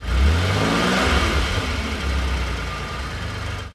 rev_out1.ogg